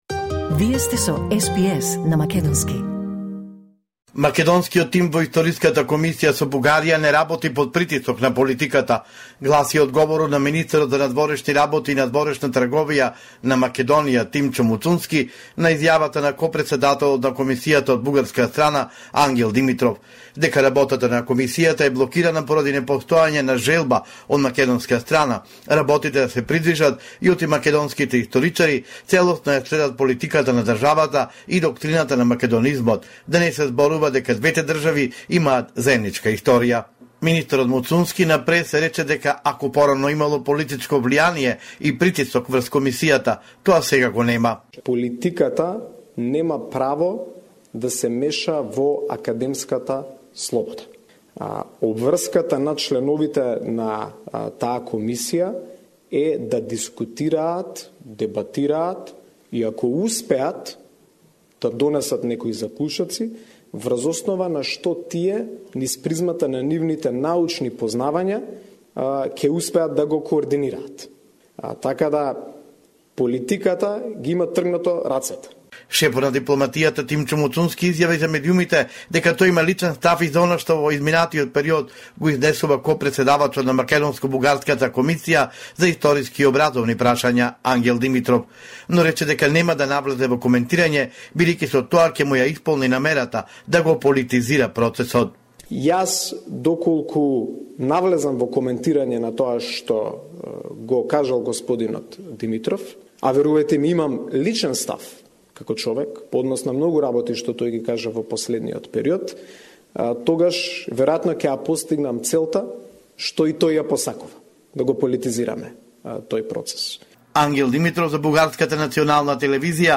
Извештај од Македонија 13 ноември 2025